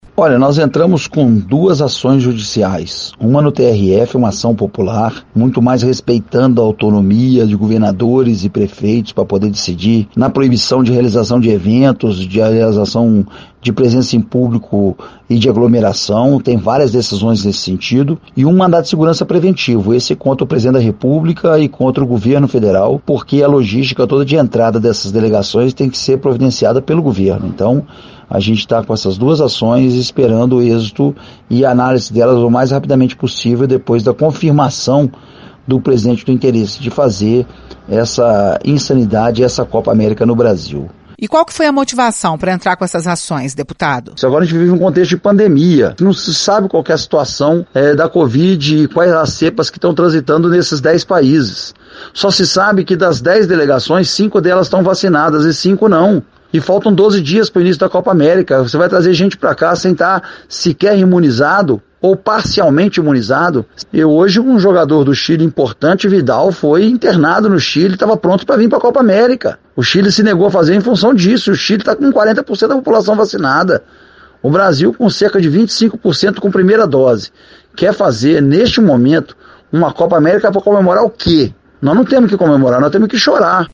A reportagem da Itatiaia conversou com o deputado Júlio Delgado, que explicou sobre as ações.